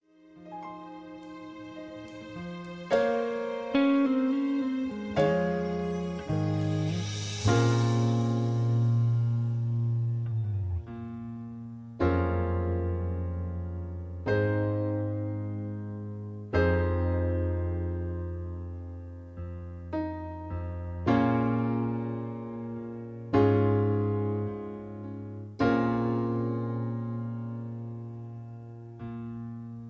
Karaoke Mp3 Backing Tracks